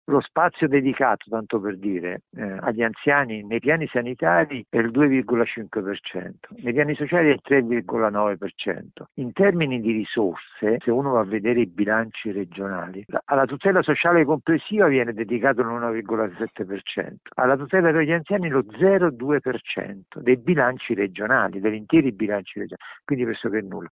Ai nostri microfoni il ricercatore